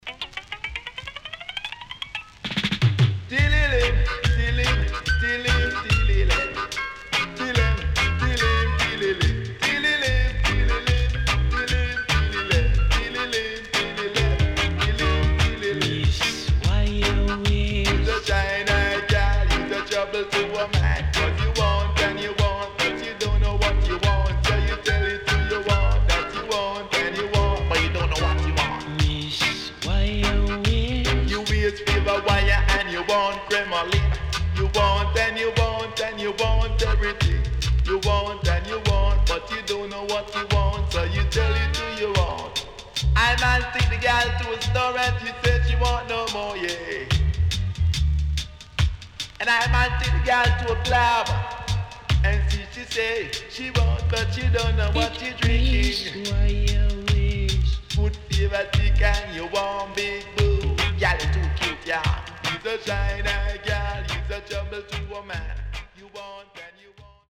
HOME > REGGAE / ROOTS  >  70’s DEEJAY
Deejay Cut
SIDE A:プレス起因により少しノイズ入ります。